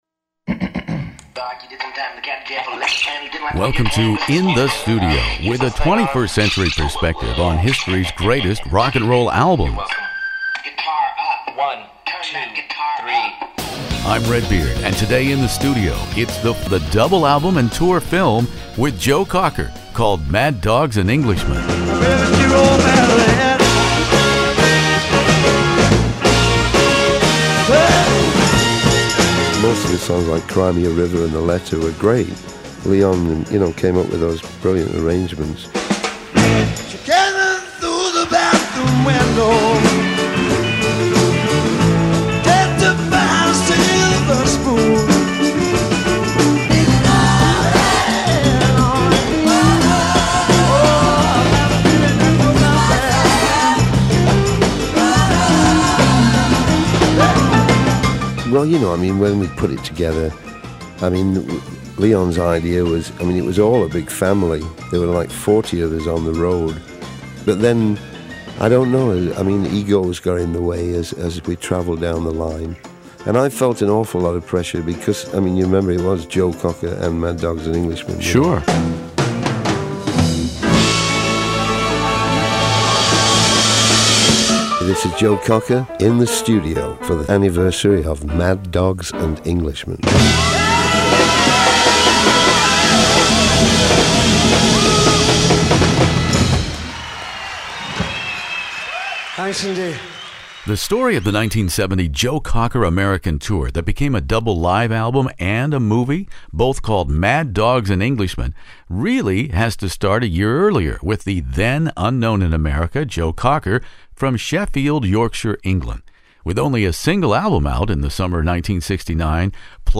Here are the first-person accounts by Joe Cocker and Leon Russell of a remarkable multi-media event, the Joe Cocker Mad Dogs and Englishmen 1970 US tour, concert film, and soundtrack double album.